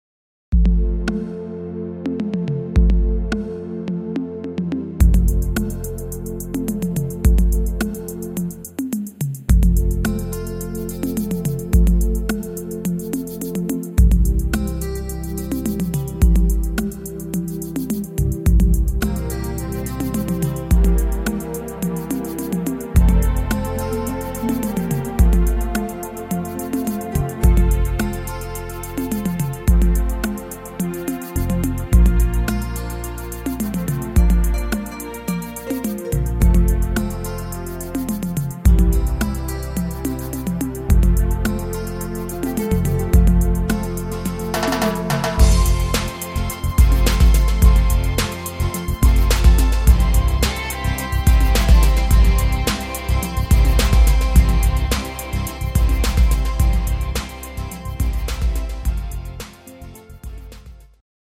Rhythmus  8 Beat
Art  Deutsch, Oldies, Schlager 90er, Weibliche Interpreten